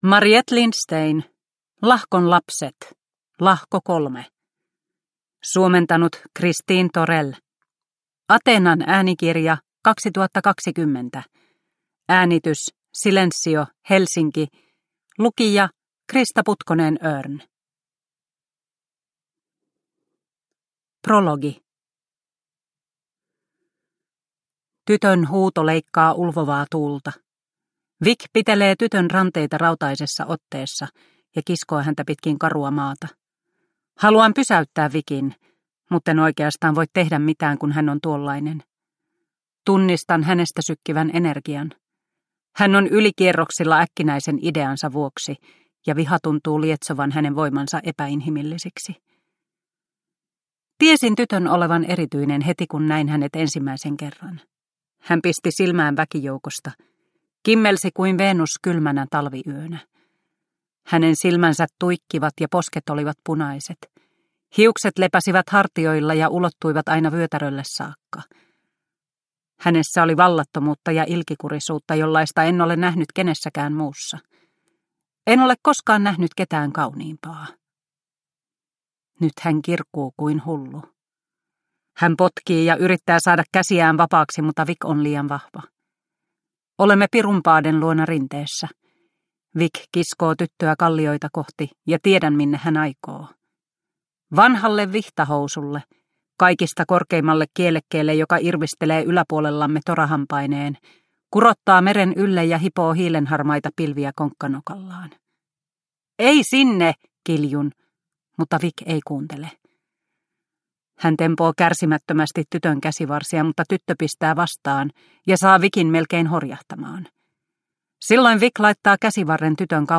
Lahkon lapset – Ljudbok – Laddas ner